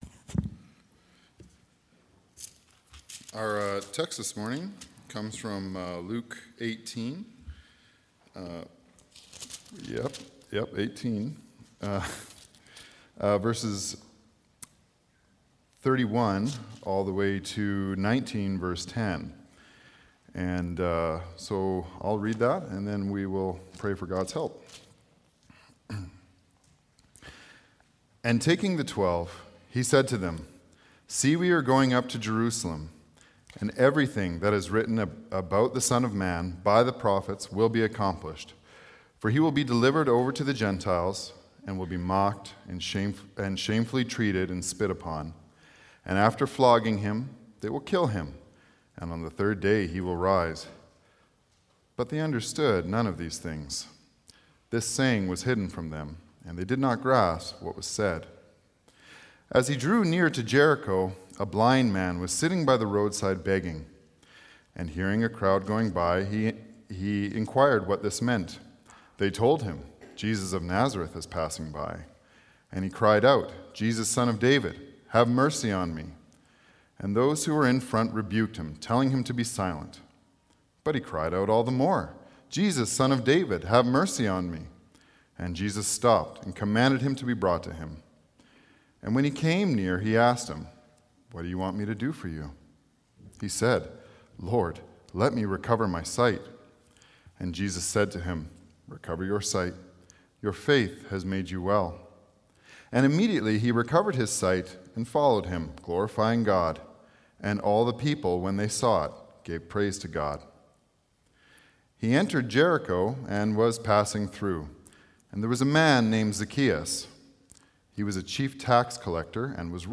Sermons | Ebenezer Christian Reformed Church
Guest Speaker